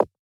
land_sound.wav